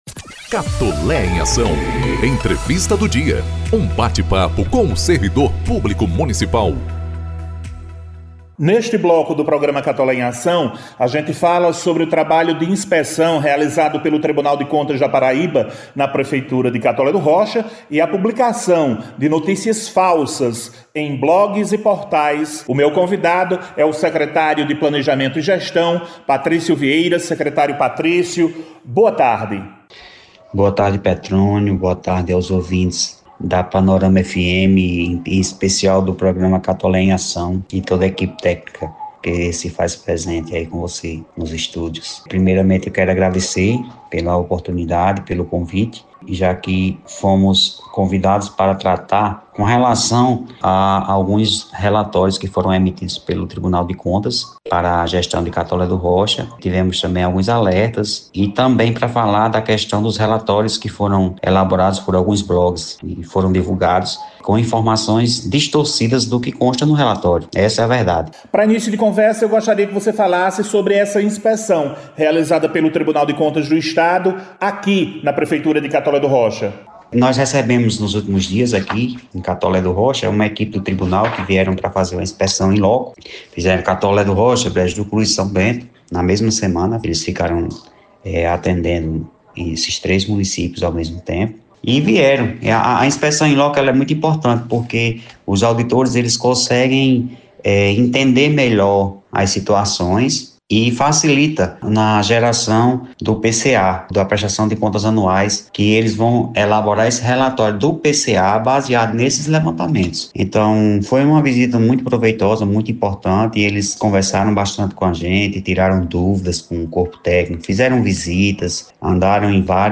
OUÇA A ENTREVISTA: O secretário de Planejamento e Gestão de Catolé do Rocha, Patrício Vieira, concedeu entrevista nesta sexta-feira ao programa Catolé em Ação, da Rádio Panorama FM, apresentado por…